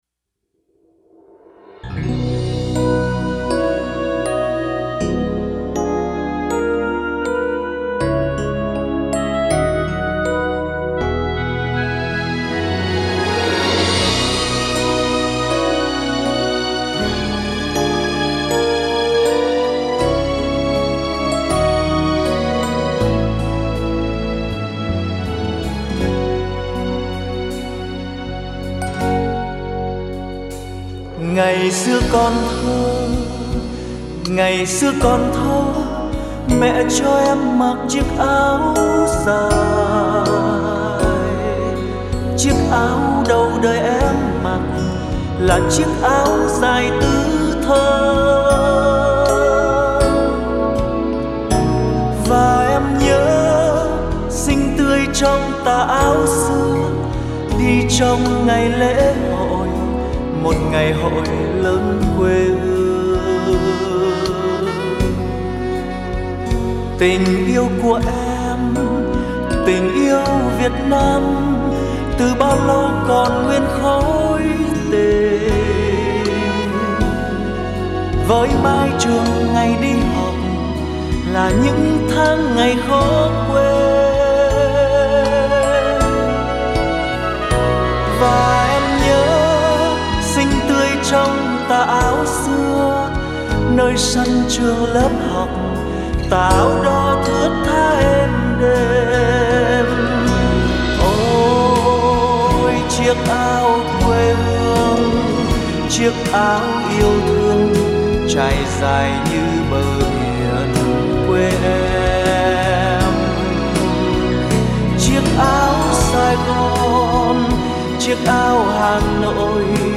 Tác giả trình bày